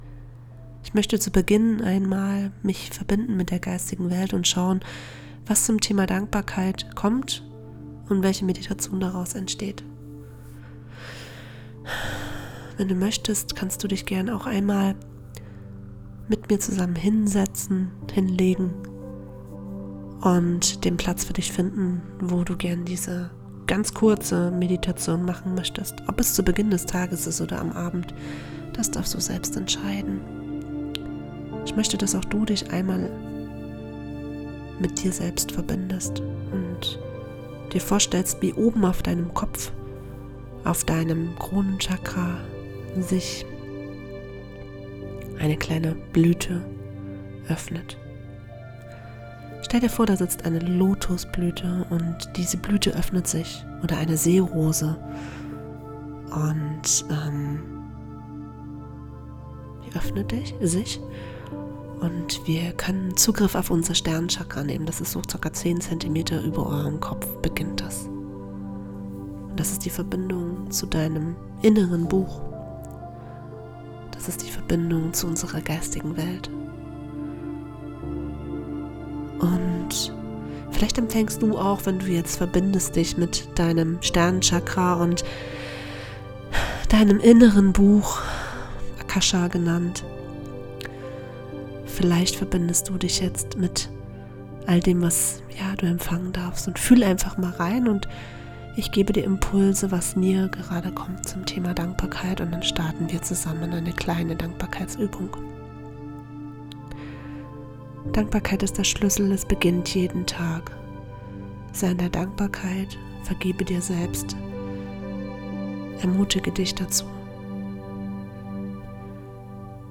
Meditation , Dankbarkeit, Liebe , Stille, Alltag
Ein Moment der Stille, um bewusst Dankbarkeit zu fühlen